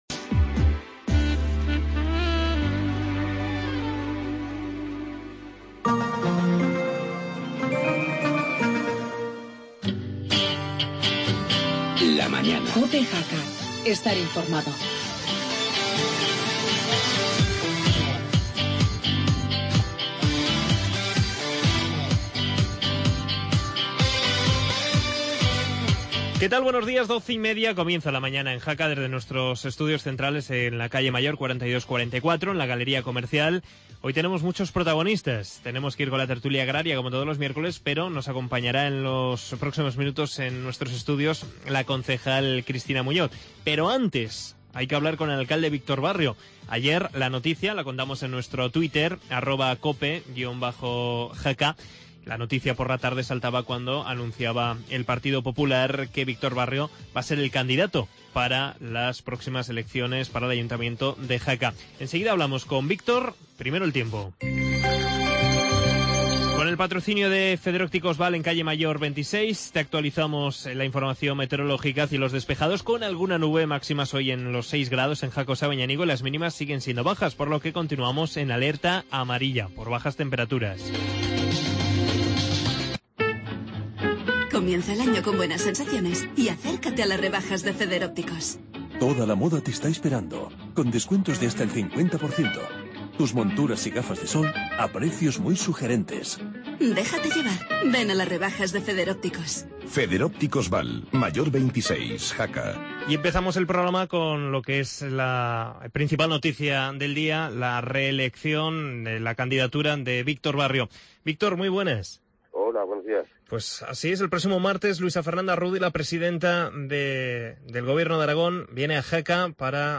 Entrevista a Víctor Barrio, alcalde de Jaca, tras conocer que el martes será presentado por el PP ARagón como candidato a la reelección.